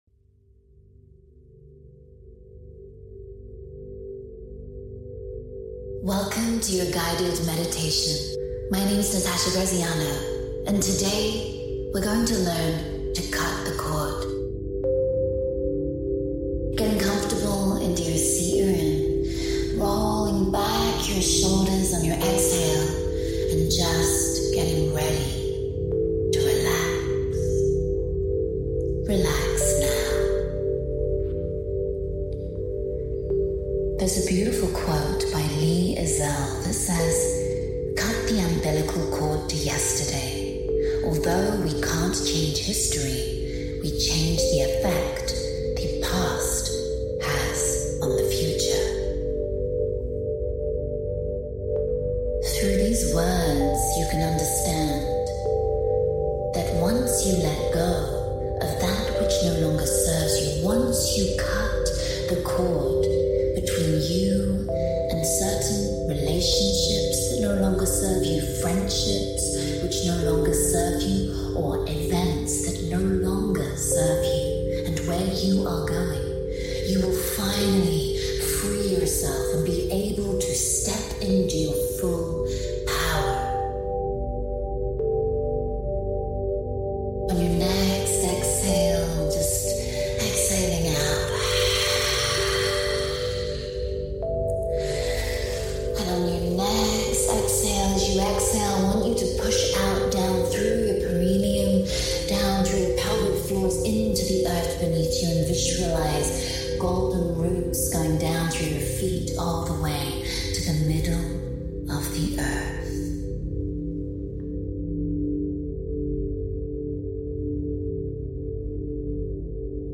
Step into 2026 lighter, clearer, and more aligned. This guided Cut the Cord Meditation is designed to help you release anything that no longer belongs in your next chapter — old emotional wounds, past relationships, limiting beliefs, or situations tha.